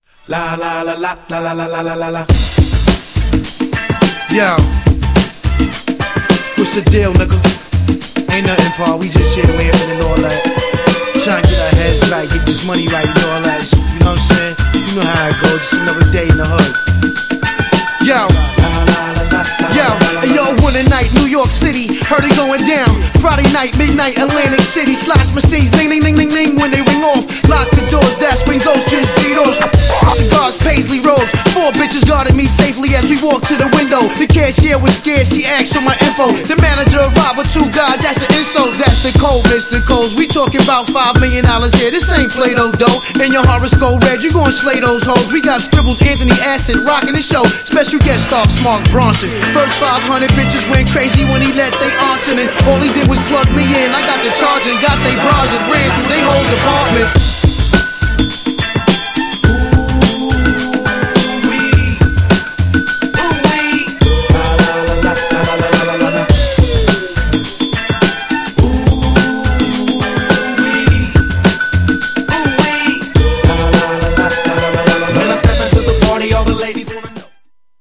GENRE Hip Hop
BPM 96〜100BPM
キャッチーなHIPHOP